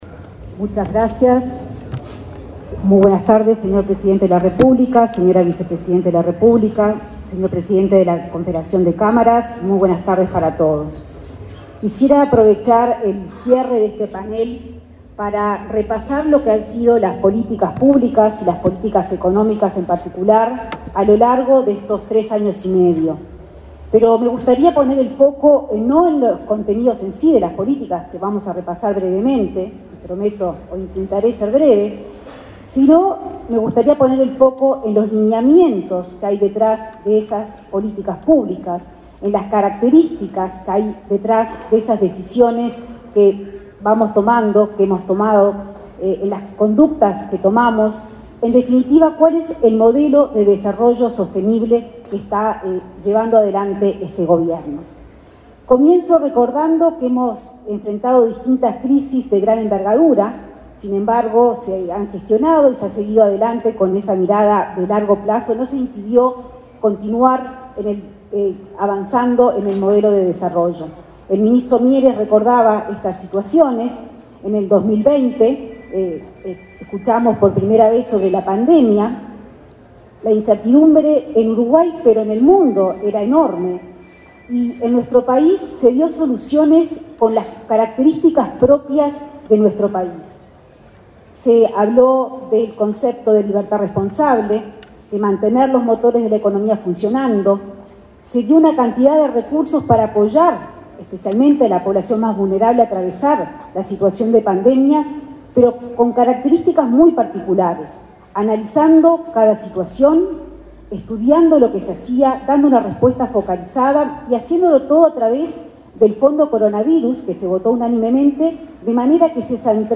Conferencia de prensa sobre los desafíos de Uruguay para lograr un desarrollo sostenible
Conferencia de prensa sobre los desafíos de Uruguay para lograr un desarrollo sostenible 08/09/2023 Compartir Facebook X Copiar enlace WhatsApp LinkedIn El presidente de la República, Luis Lacalle Pou, asistió, este 8 de setiembre, a una conferencia de prensa sobre los desafíos de Uruguay para lograr un desarrollo sostenible, organizada por las Cámaras Empresariales en la Expo Prado 2023. Participaron los ministros de Economía y Finanzas, Azucena Arbeleche; de Trabajo y Seguridad Social, Pablo Mieres, y de Transporte y Obras Públicas, José Luis Falero.